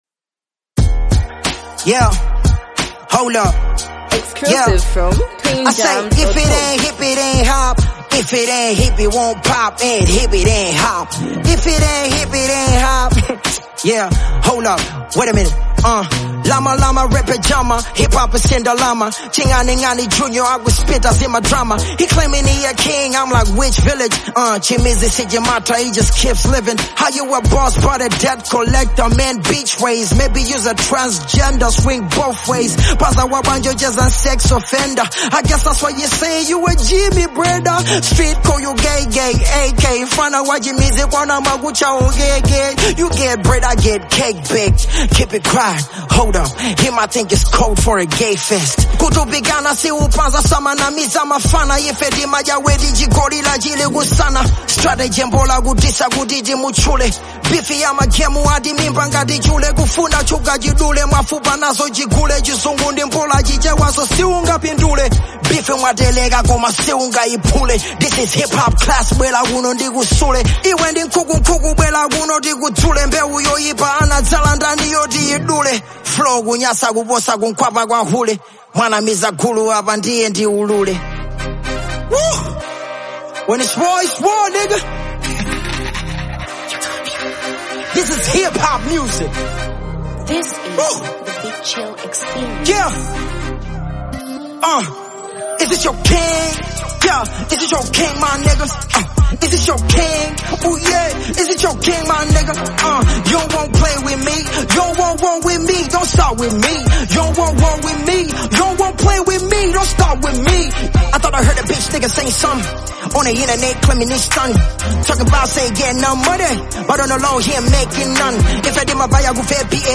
diss track
and misplaced loyalty within the local hip-hop scene.